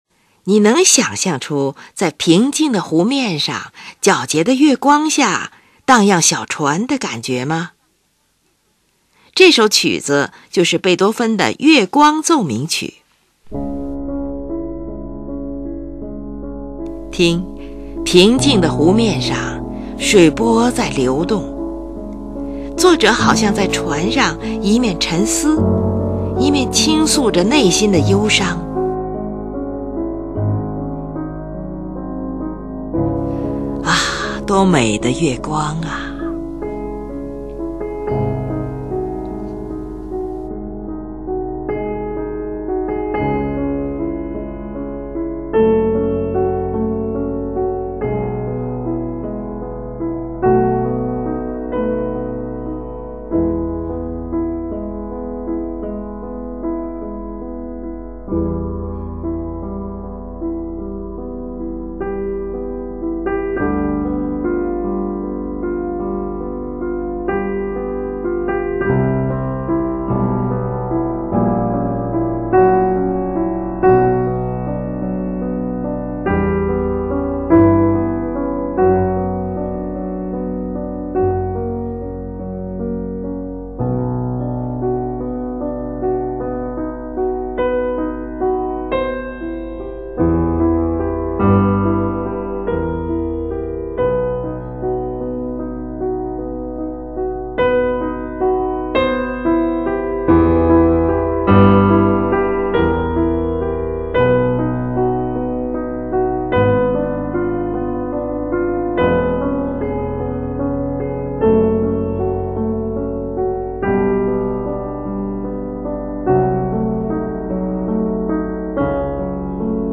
第一乐章是建立在升c小调上的。这是一个持续的慢板。
整个乐曲都由不断流出的三连音符来贯穿，使音乐有着无限的动感。
A段的旋律较平缓，尤其是与三连音的频率相比，显得更加宽舒。
在结尾处，乐句不断地重复着，仿佛在对离开感到依依不舍。